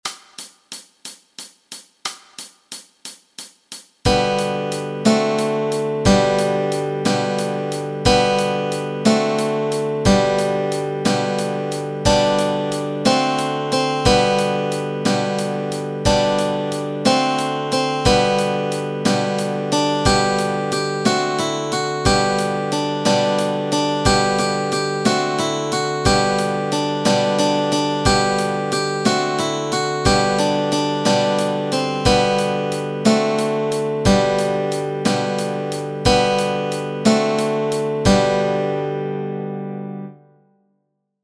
Melody & Chords Track
Three-Blind-Mice-melody-and-chords.mp3